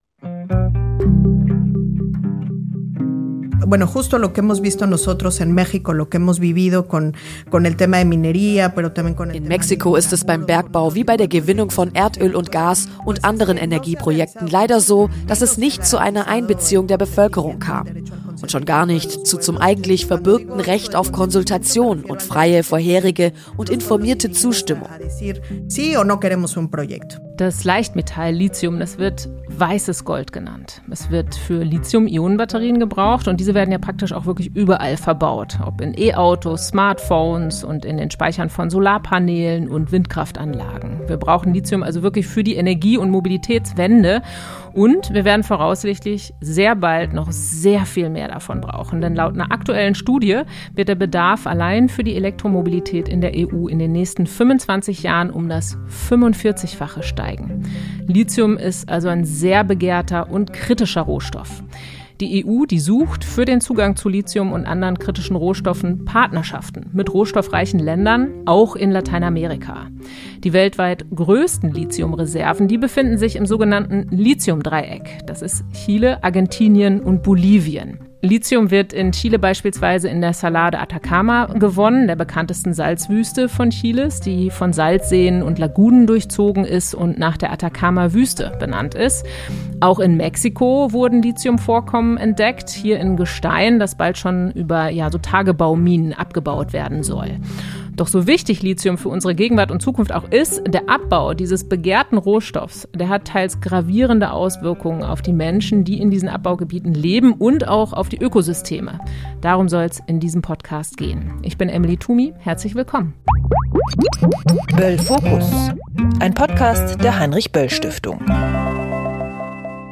Aber was bedeutet der Abbau in Lateinamerika für Mensch und Umwelt? Zwei Expert*innen aus Mexiko und Chile berichten.